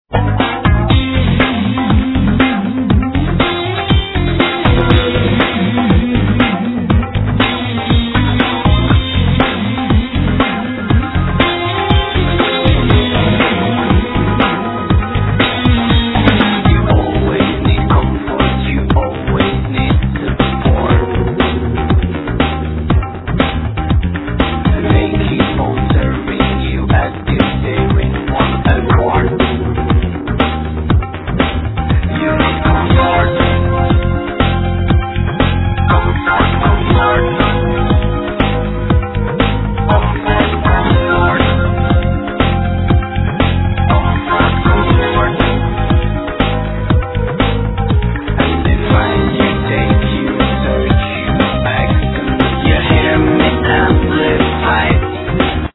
Vocals, Guitar
Bass
Guitar, Guitar synth
Keyboards
Violin
Percussions